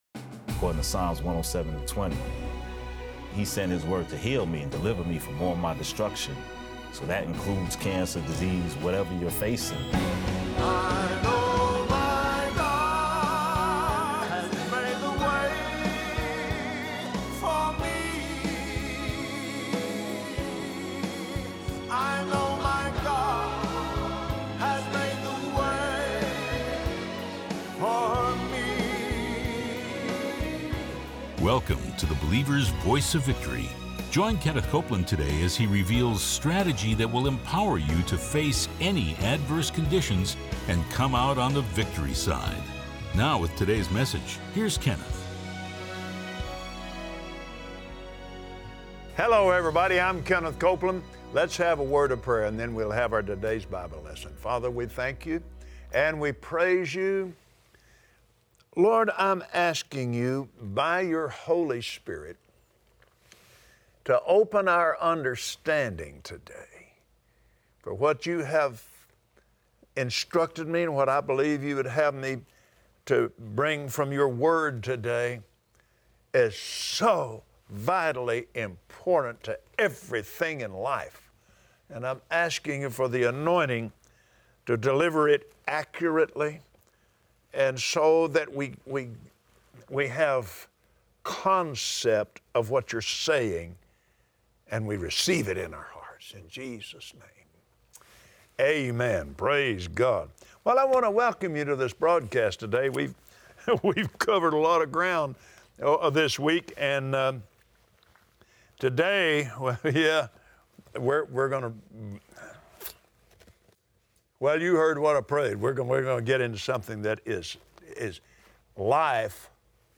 Believers Voice of Victory Audio Broadcast for Friday 08/04/2017 You can build your faith muscle just like you can build natural muscle! Watch Kenneth Copeland on Believer’s Voice of Victory as he shares how persistence and diligence in developing the measure of faith inside you will always produce strong faith.